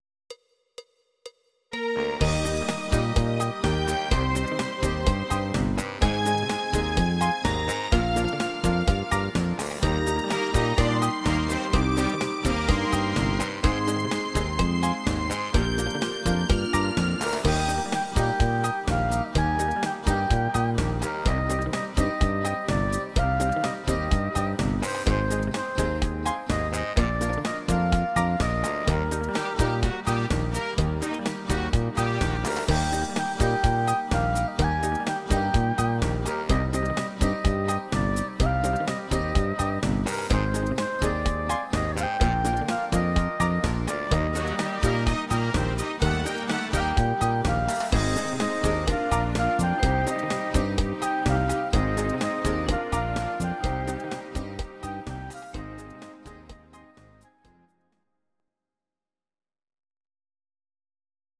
These are MP3 versions of our MIDI file catalogue.
Please note: no vocals and no karaoke included.
Latin version